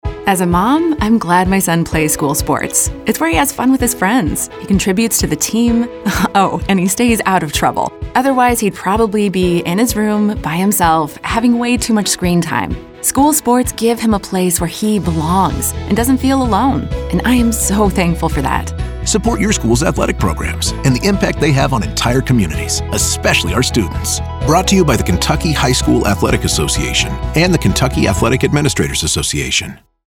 25-26 Radio – Public Service Announcements